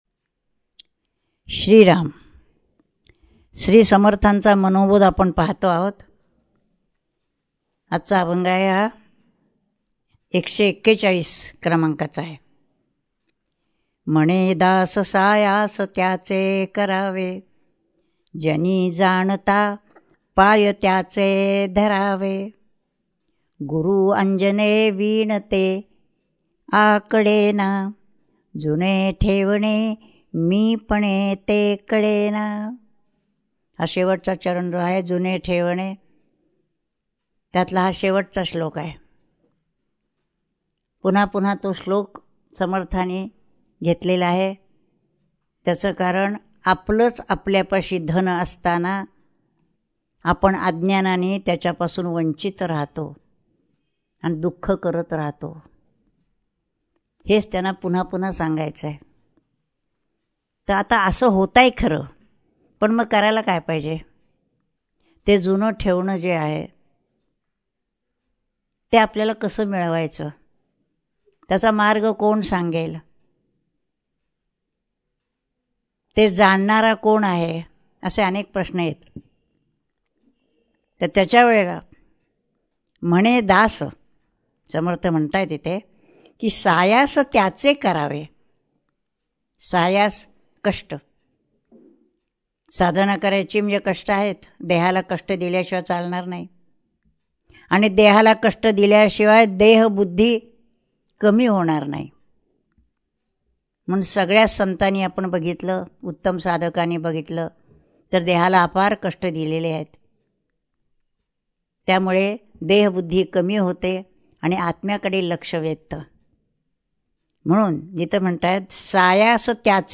श्री मनाचे श्लोक प्रवचने श्लोक 141 # Shree Manache Shlok Pravachane Shlok 141